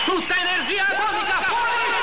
Type: Sound Effect